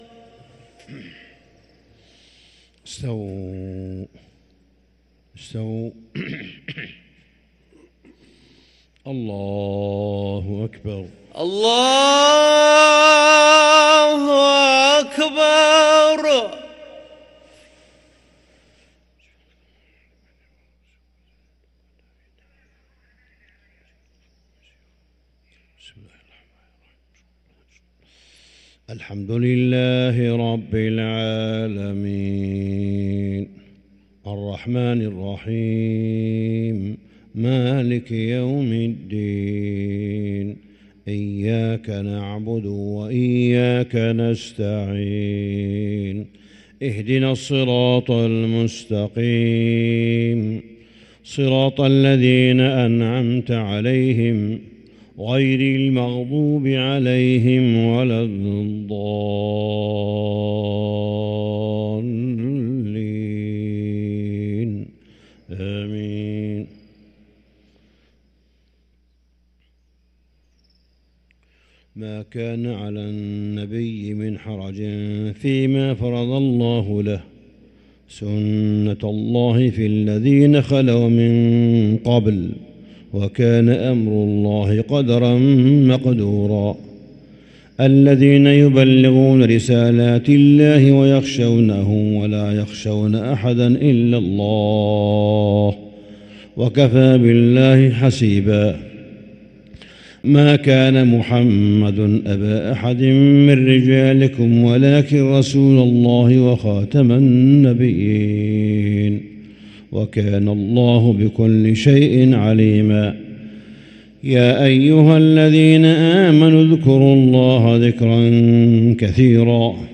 صلاة الفجر للقارئ صالح بن حميد 2 رمضان 1444 هـ